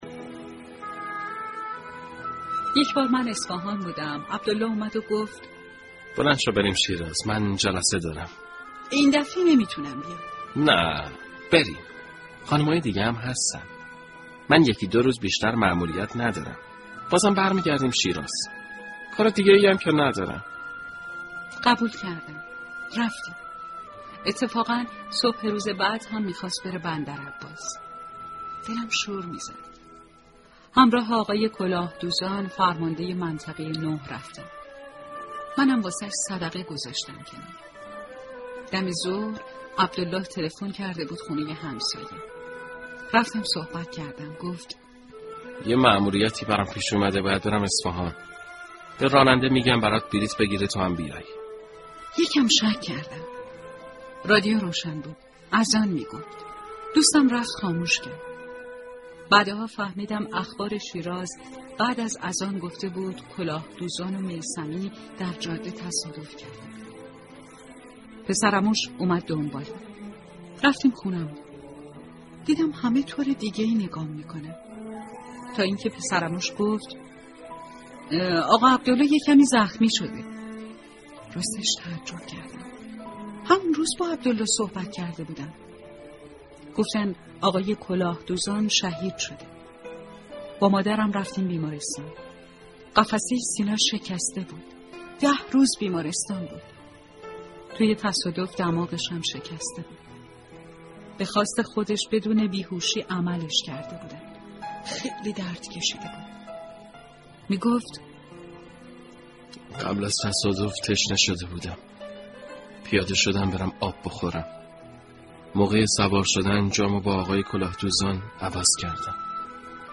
صوت روایتگری
ravayatgari52.mp3